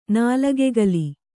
♪ nālagegali